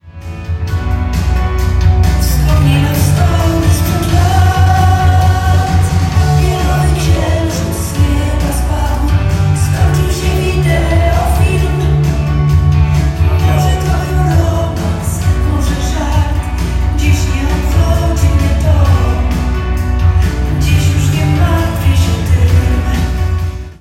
legendarna polska wokalistka
13 września dla publiczności w kościele pw.